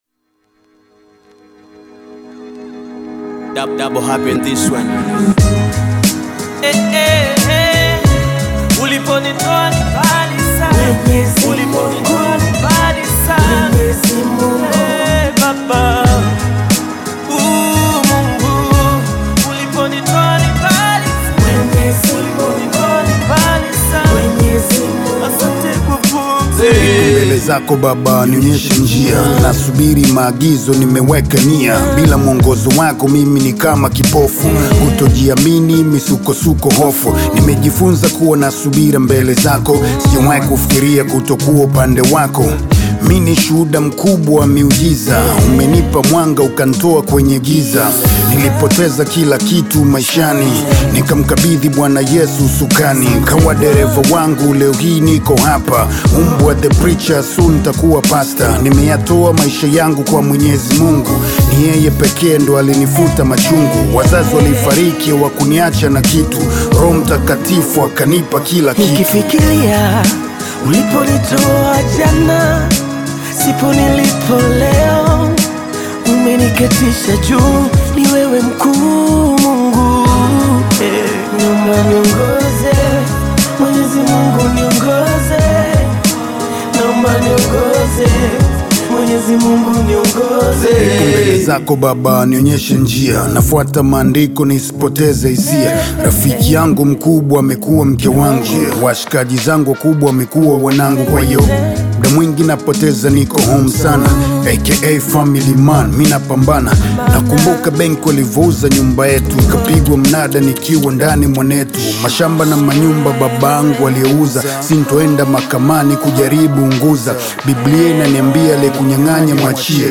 Tanzanian Bongo Flava artist, rapper, singer and songwriter
Bongo Flava